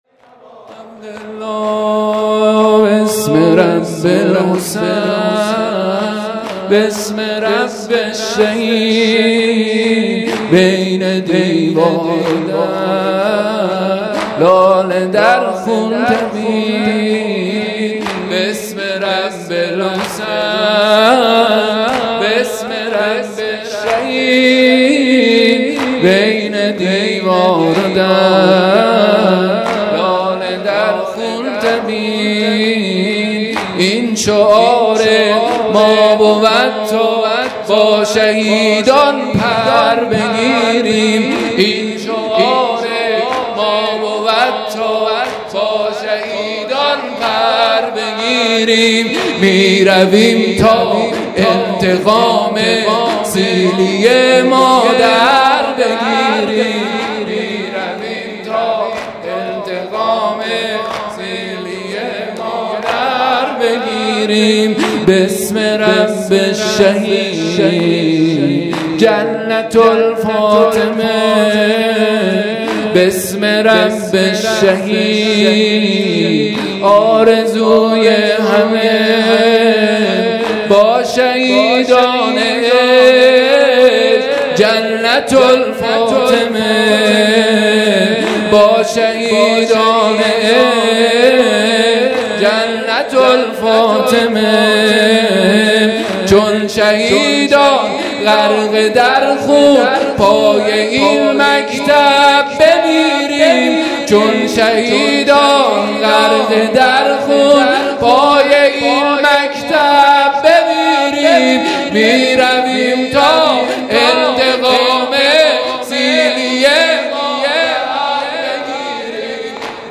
شور ۲ | بسم رب الحسین مداح